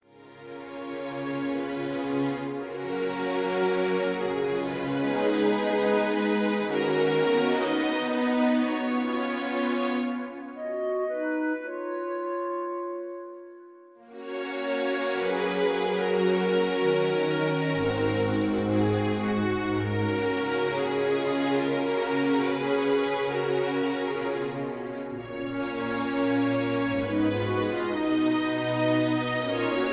key-F#, Tono de F#